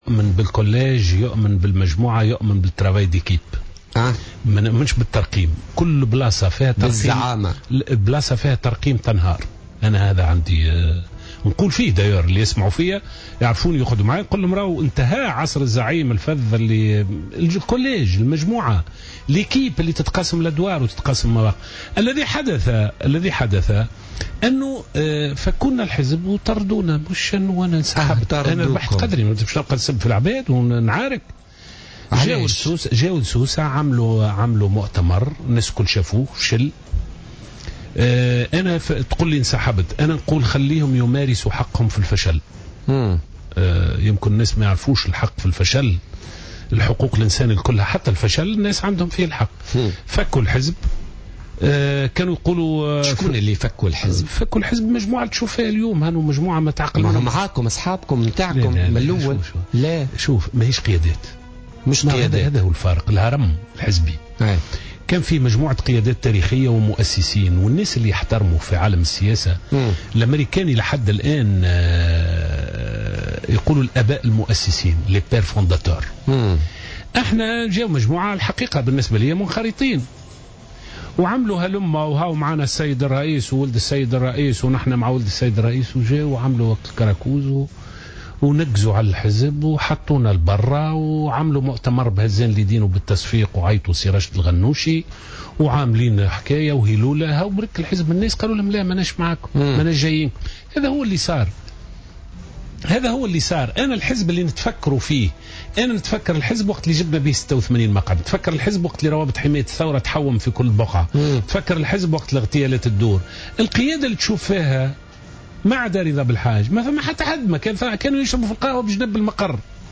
قال القيادي السابق في نداء تونس، لزهر العكرمي ضيف برنامج "بوليتكا" اليوم الأربعاء إن ما حدث داخل حزب النداء هو عملية سطو، مضيفا أن مؤتمر سوسة مؤتمر "فاشل وباطل".